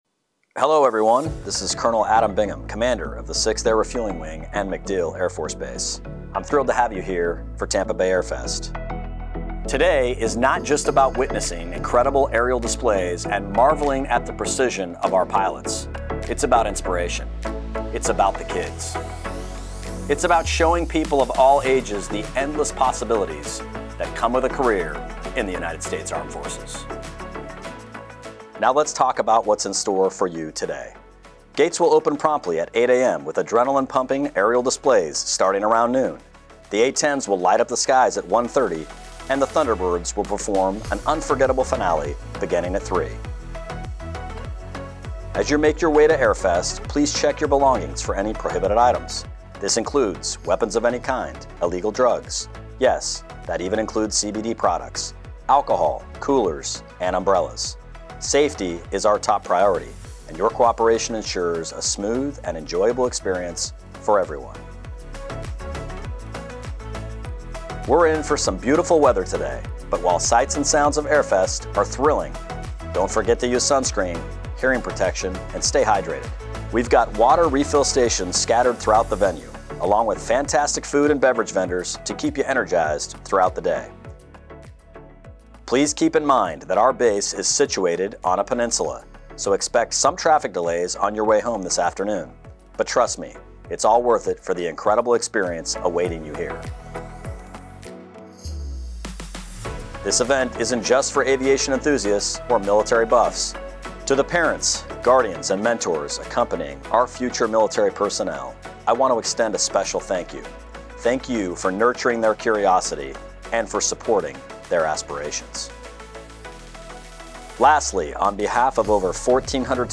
Morning radio spot for the tram ride at Tampa Bay AirFest, MacDill Air Force Base, Florida, March 29 and 30.